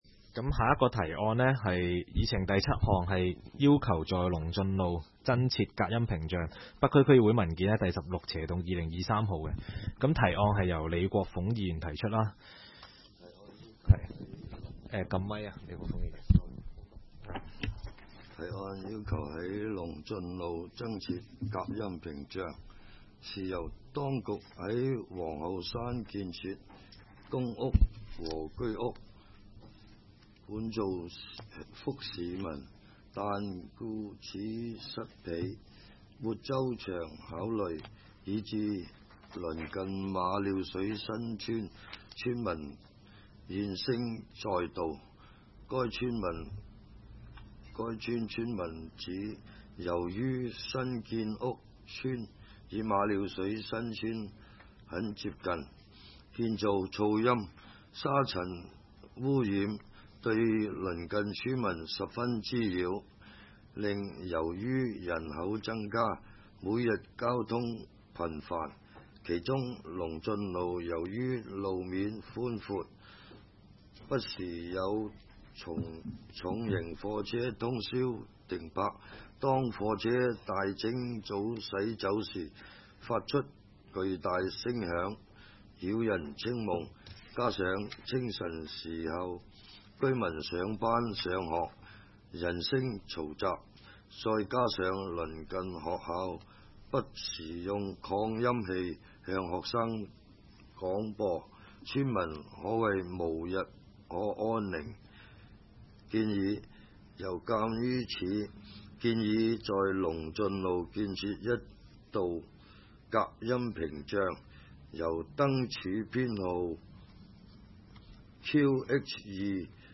区议会大会的录音记录
北区民政事务处会议室